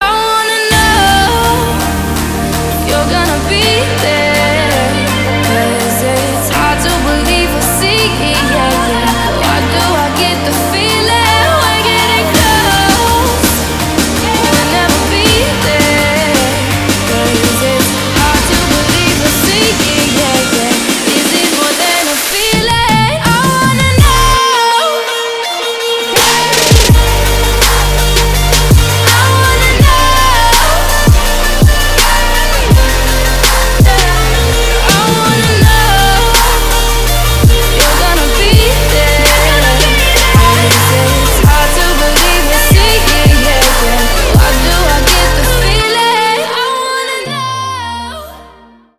• Качество: 320, Stereo
громкие
Electronic
EDM
Trap
красивый женский голос
future bass